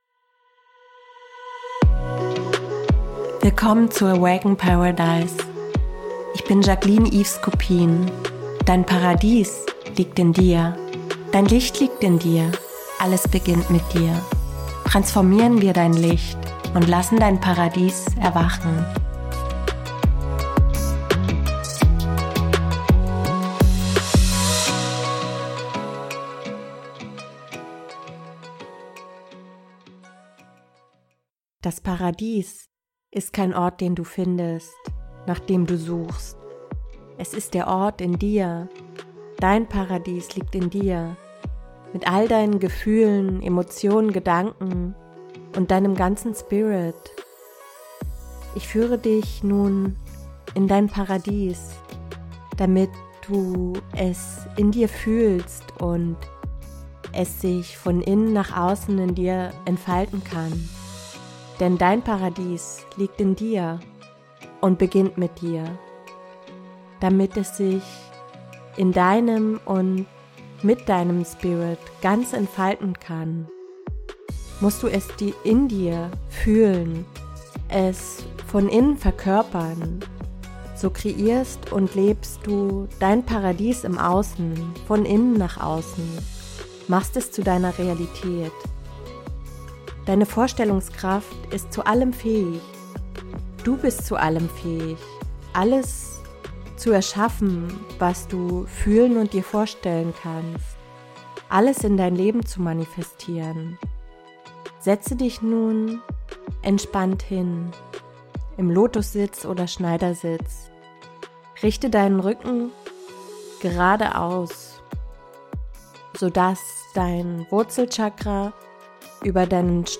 Diese upliftende, moderne Meditation führt dich über einen Regenbogen mit seinen sieben Farben, die an die sieben Chakren angelehnt sind, in dein Paradies.